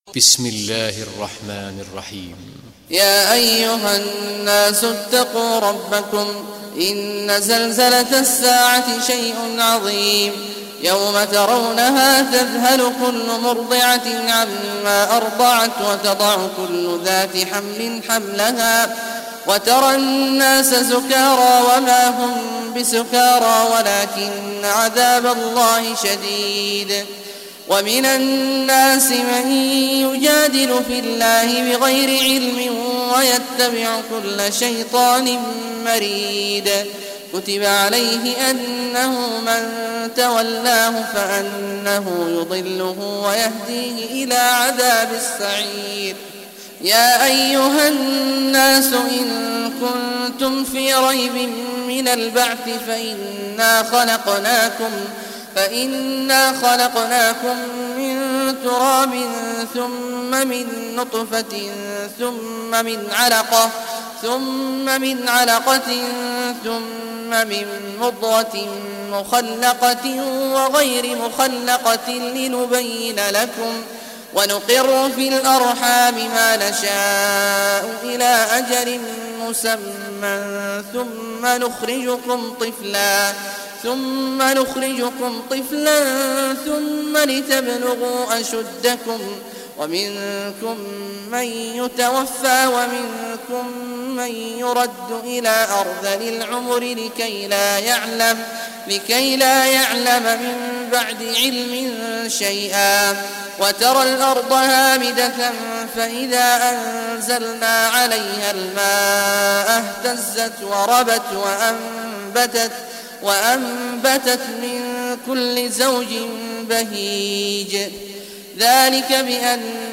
Surah Al-Haj Recitation by Sheikh Awad al Juhany
Surah Al-Haj, listen or play online mp3 tilawat / recitation in Arabic in the beautiful voice of Sheikh Abdullah Awad al Juhany. Download audio tilawat of Surah Al-Haj free mp3 in best audio quality.